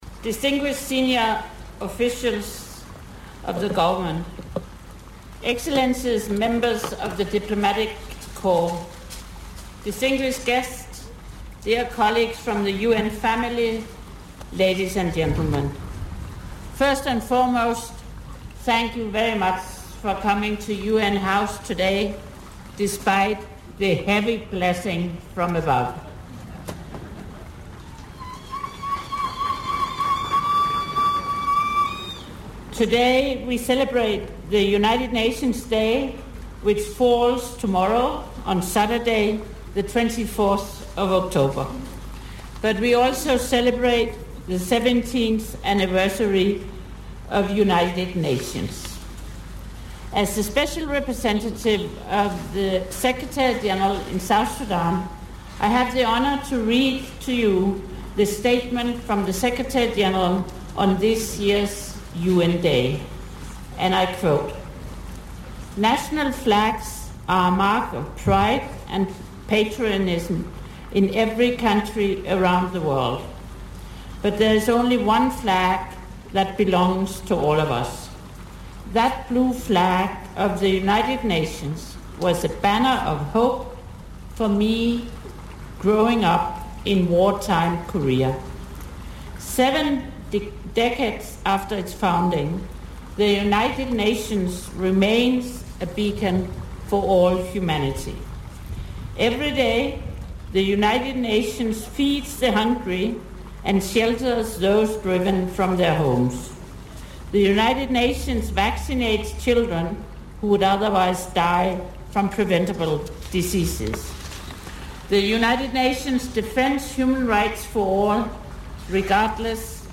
Members of the United Nations Family in South Sudan took part in a flag raising ceremony on the 70th anniversary of the United Nations Day.
Both flags of South Sudan and the United Nations were raised at exactly 12:34pm as the anthems of South Sudan and the United Nations played in the background.
The SRSG in South Sudan, Ellen Margrethe Løj, delivered this statement emphasizing the need for Peace in South Sudan.